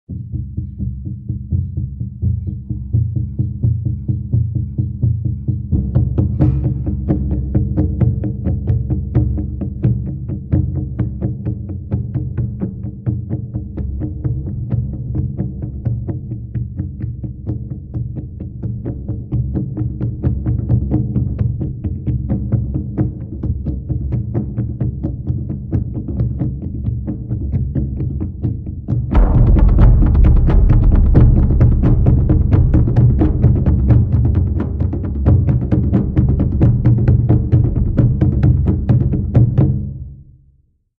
На этой странице собраны разнообразные звуки малого барабана: от четких ударов до сложных ритмических рисунков.